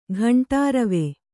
♪ ghaṇṭārave